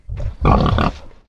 boar_idle_2.ogg